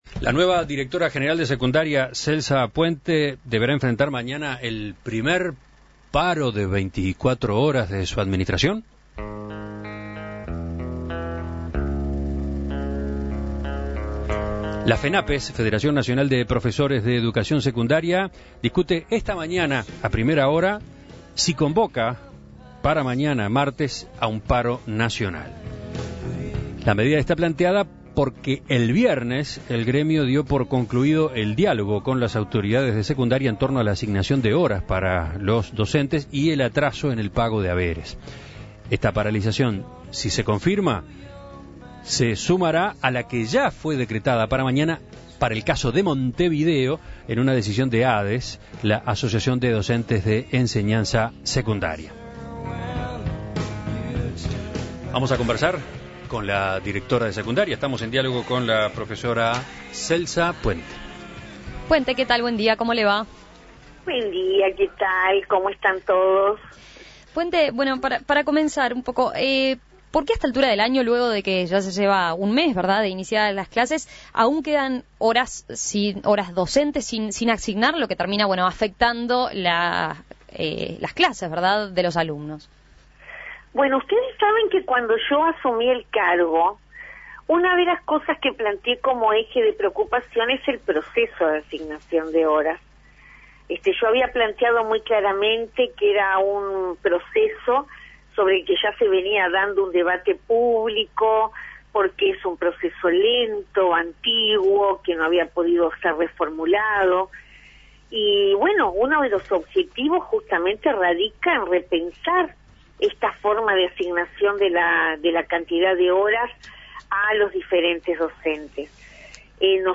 La profesora Puente conversó con En Perspectiva a propósito de la medida (que al momento de la entrevista no había sido tomada aún) y sobre las posibles soluciones para la elección de horas docentes, que a su entender es un procedimiento "lento y antiguo" y no ha podido ser reformulado.